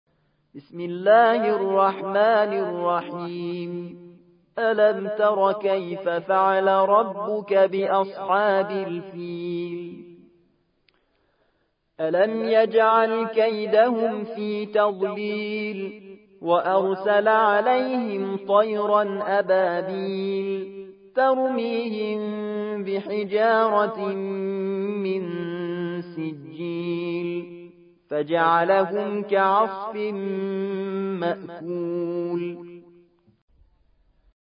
105. سورة الفيل / القارئ